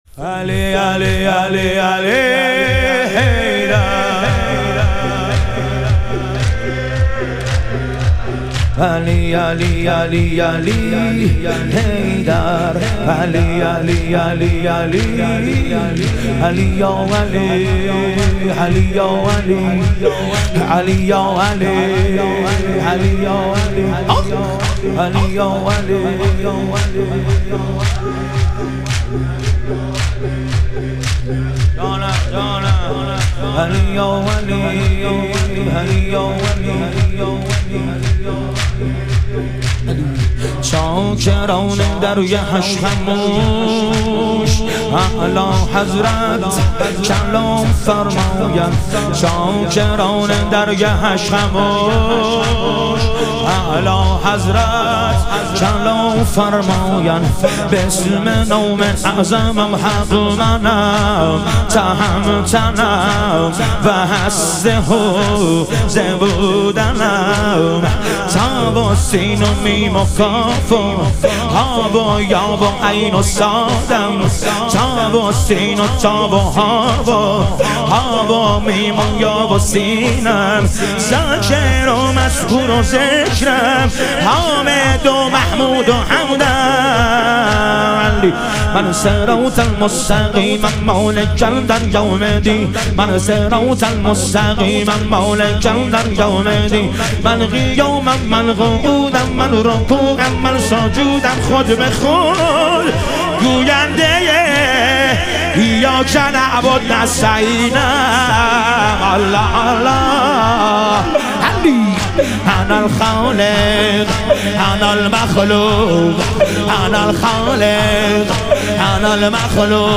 ایام فاطمیه دوم - تک